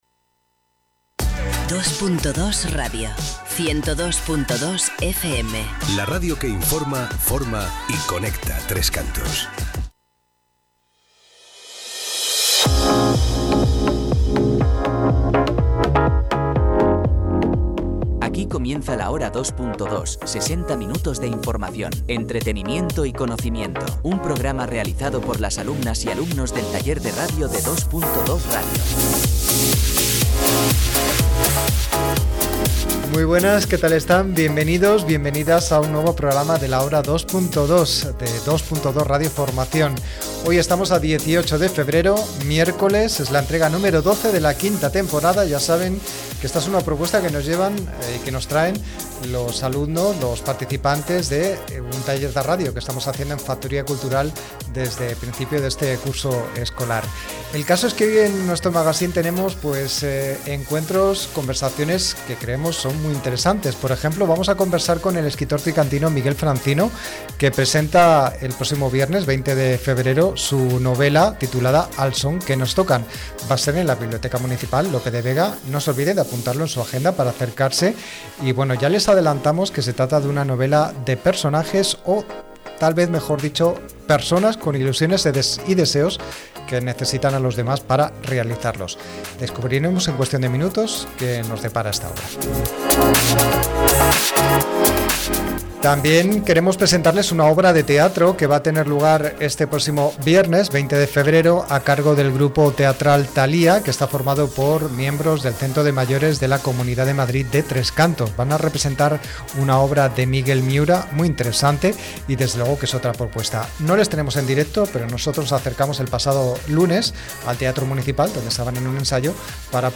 Hoy 18 de febrero de 2026 es la entrega número doce de la quinta temporada del magazine La Hora 2.2 de Dos.Dos Radio Formación. Les acompañaremos durante una hora con contenidos que han desarrollado los integrantes del taller de radio que hacemos en Factoría Cultural.